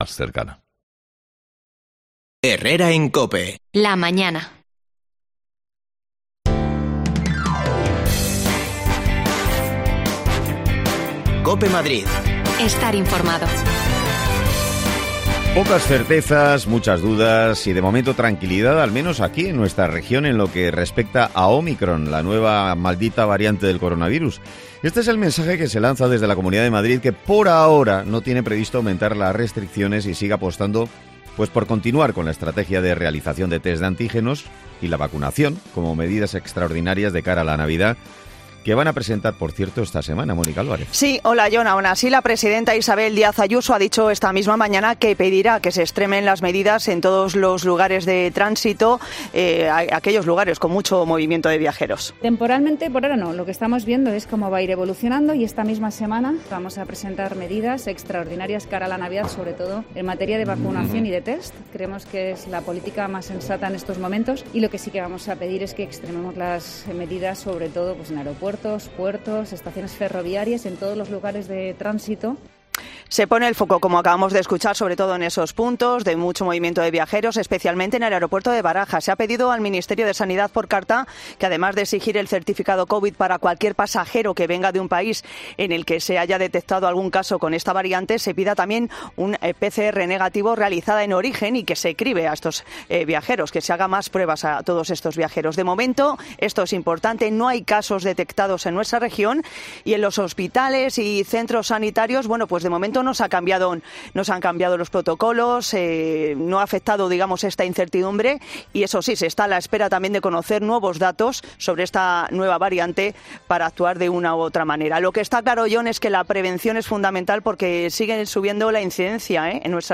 desconexiones locales